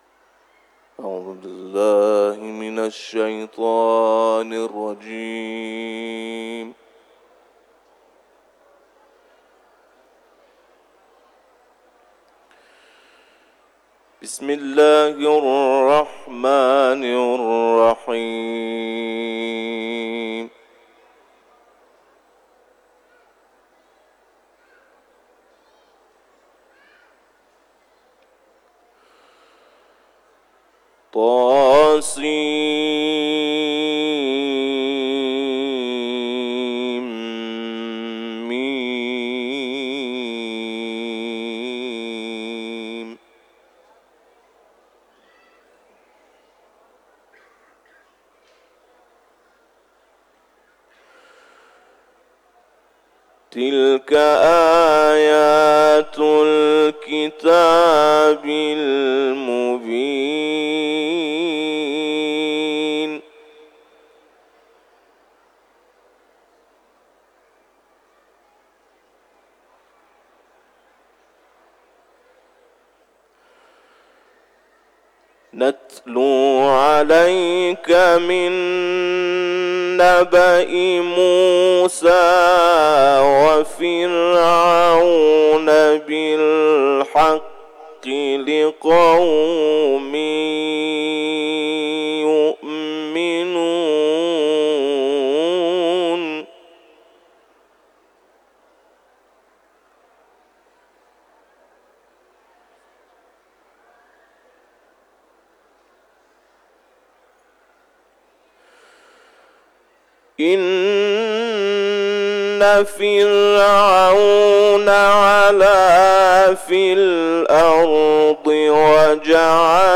در جوار بارگاه حضرت رضا(ع)
تلاوت
حرم مطهر رضوی ، سوره شعرا